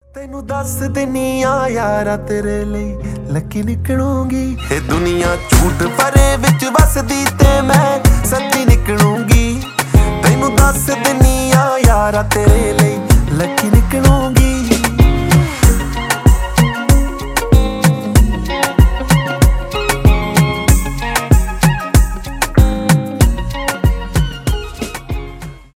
поп
индийские